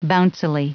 Prononciation du mot bouncily en anglais (fichier audio)
Prononciation du mot : bouncily